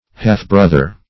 \Half"-broth`er\ (-br[u^]th`[~e]r)